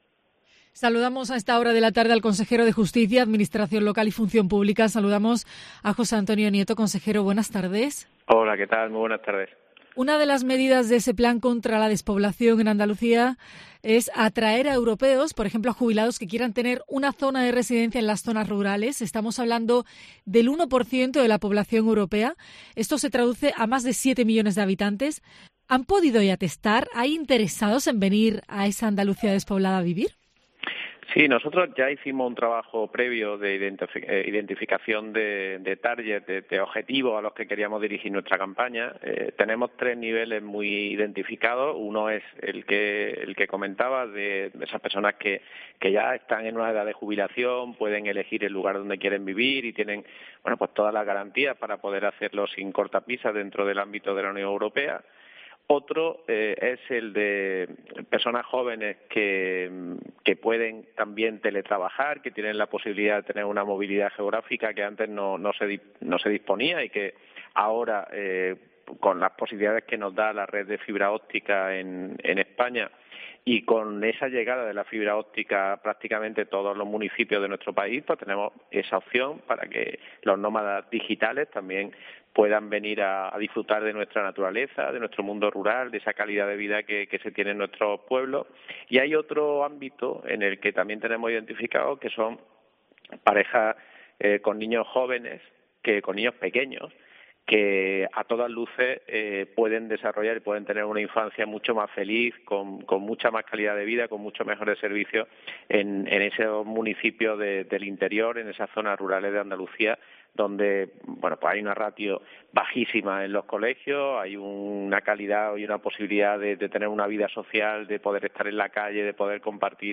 En una entrevista a COPE Andalucía , el Consejero José Antonio Nieto ha defendido la calidad de vida que ofrecen estos municipios, alejados del estrés de las grandes ciudades .